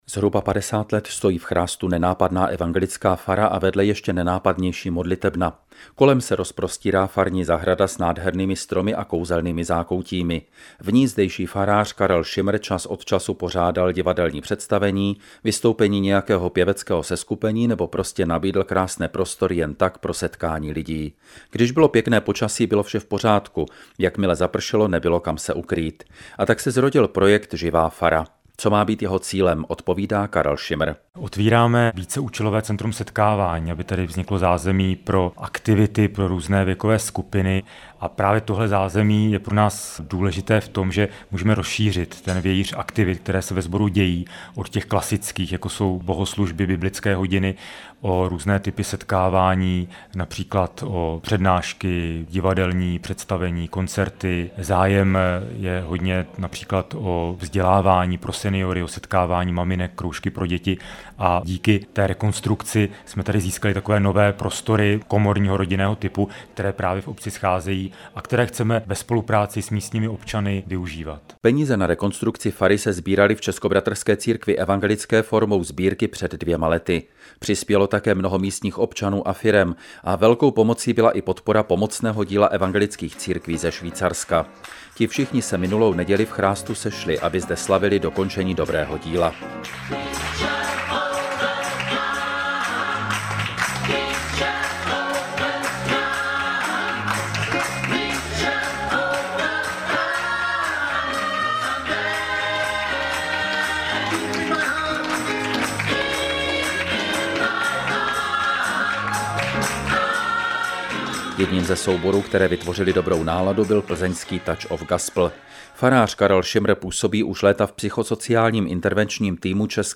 Reportáž z 2.6.2013 najdete v příloze ve formátu MP3.